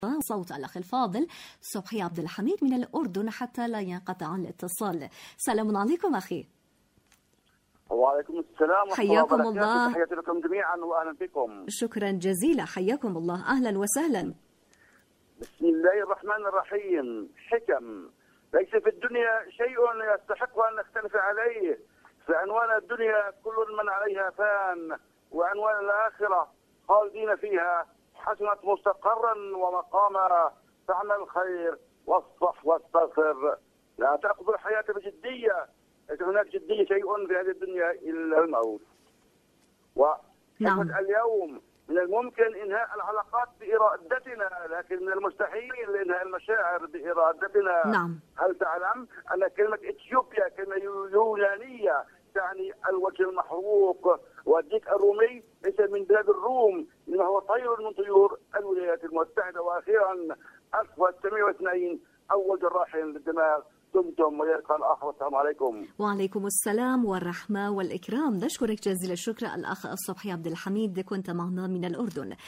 المنتدى الإذاعي/مشاركة هاتفية